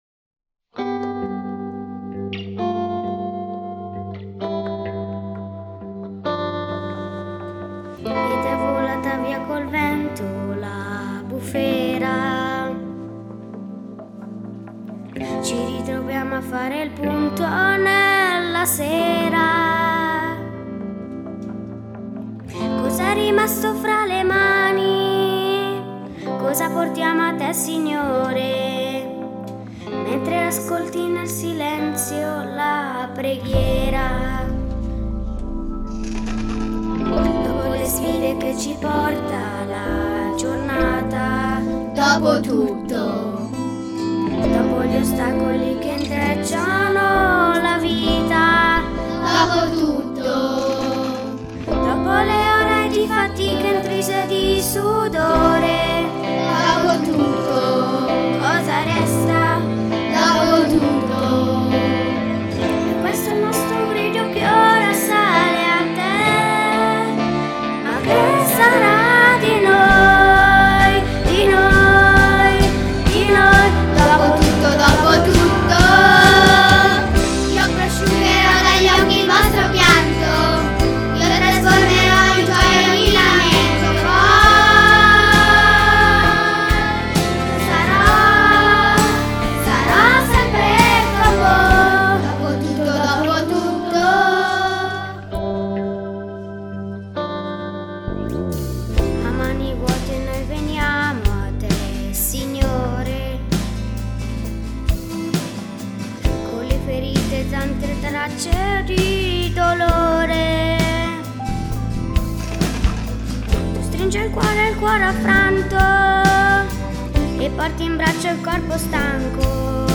We also have recorded songs by our talented kids singing group of which you can download onto your PC.